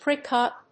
アクセントpríck úp